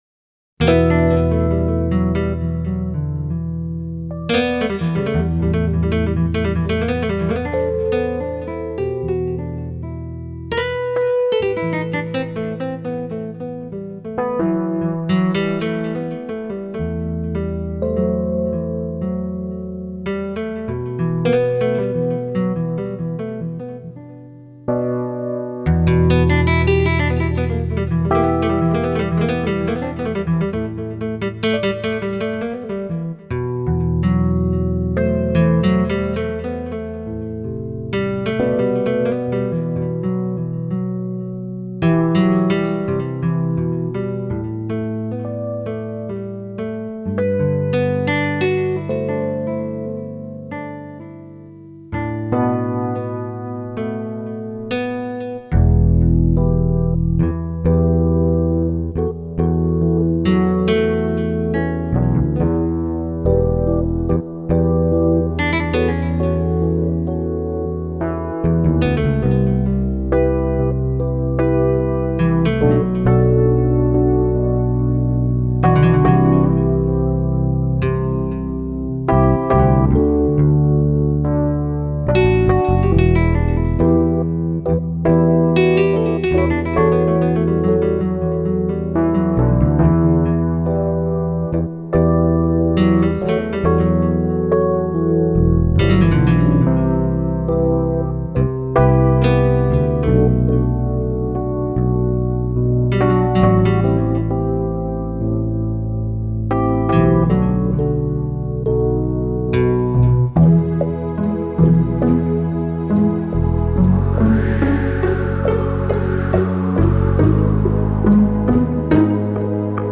Пими ® » Музика » Massage Music